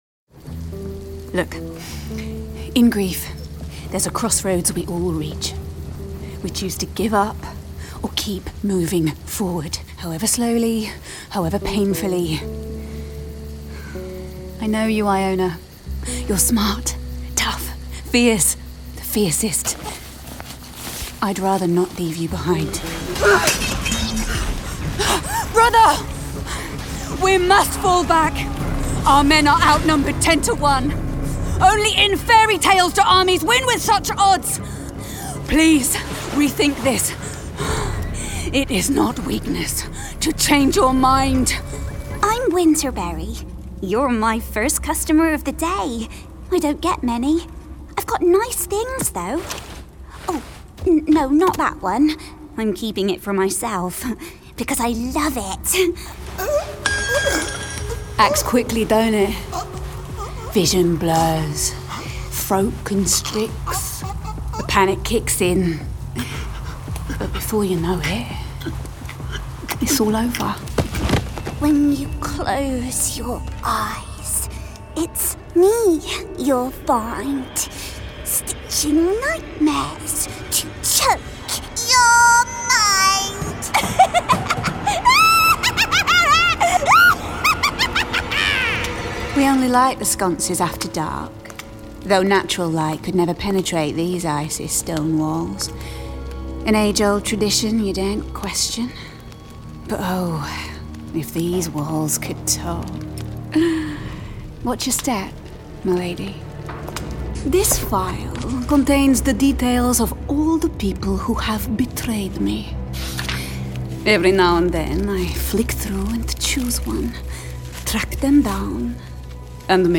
Gaming Showreel
Known for her excellent comic timing, she brings a dynamic edge and is always ready to surprise with a wild card.
Female
Neutral British
Warm
Youthful